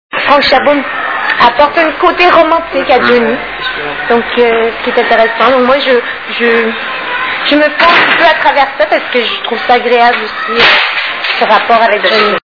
STARMANIA...Interview
( Casino de Paris, Hall d'entrée, 06/02/2000 )